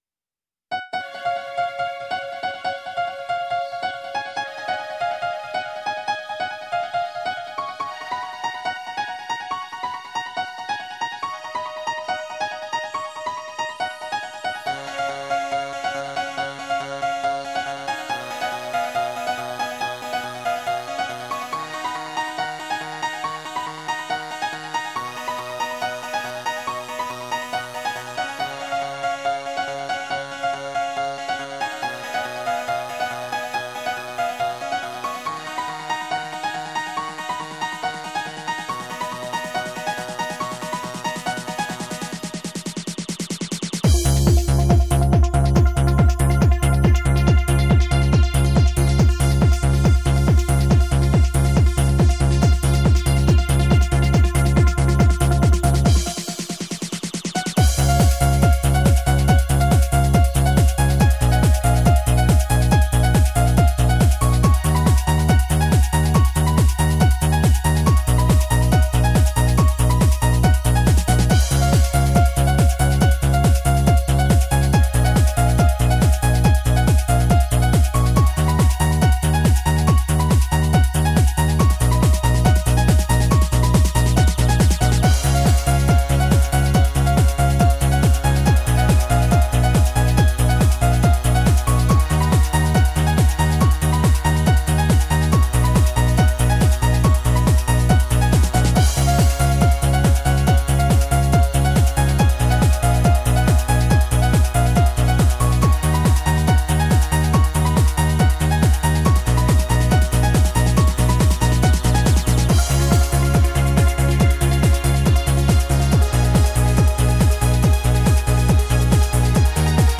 Жанр: Progressive House